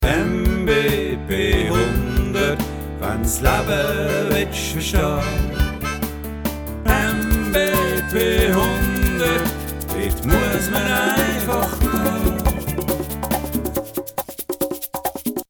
Der Klingelton zum Modul